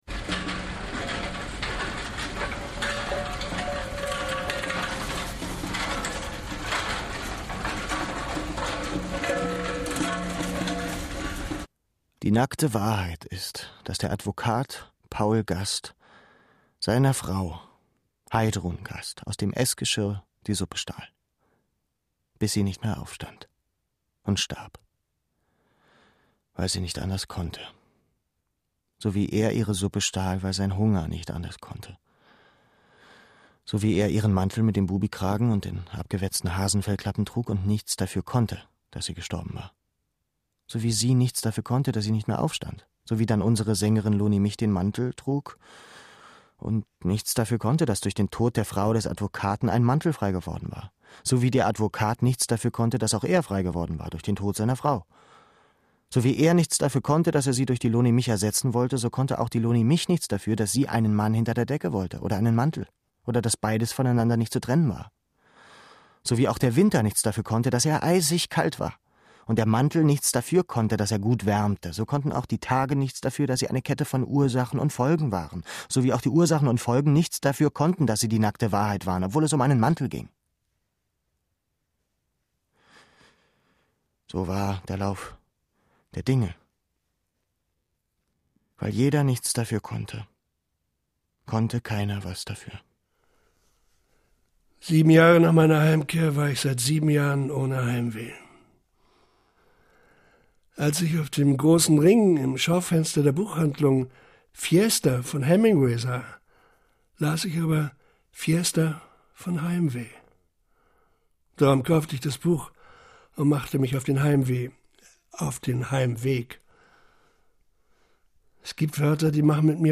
Radio | Hörspiel
Hörpiel nach dem gleichnamigen Roman
Alexander Fehling und Vadim Glowna teilen sich wirkungsvoll differenziert die Rolle des Auberg: Der junge Leo, der als 17-Jähriger am 15. Januar 1945 um drei Uhr nachts von einer Patrouille geholt wird und mit anderen Siebenbürger Sachsen als Büßer für die Kriegsschuld der Deutschen in Viehwaggons in das Zwangslager Nowo Gorlowka in die ukrainische Steppe deportiert wird.
Der gespenstische Überlebenskampf zwischen der „Herzschaufel“, dem Delirium „Atemschaukel“ und dem „Hungerengel“ wird von Otto Mellies in flüsternden Echos bis an den Rand des Wahnsinns getrieben.